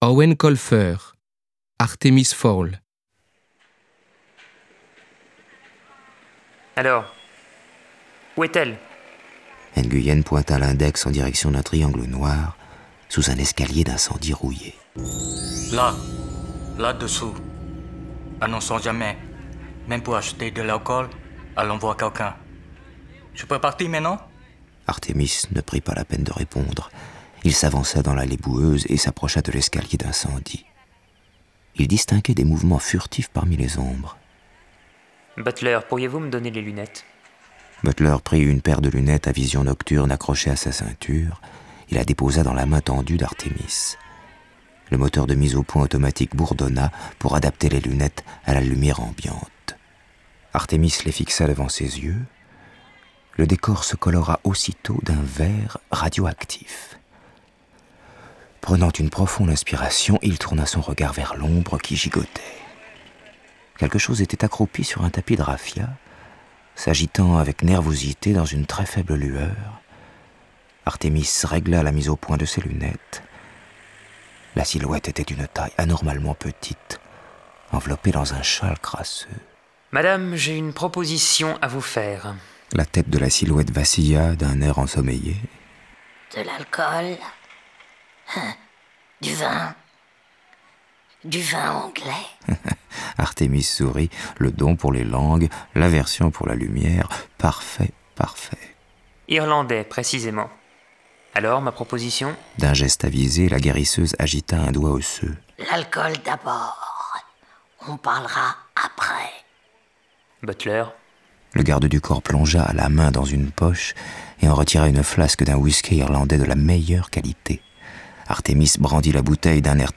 interview de Eoin Colfer